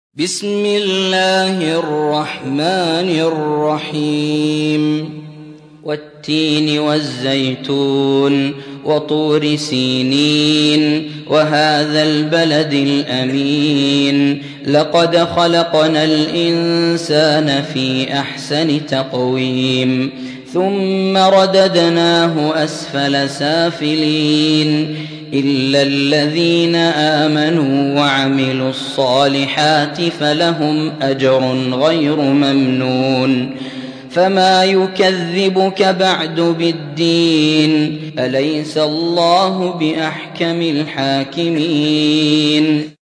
95. سورة التين / القارئ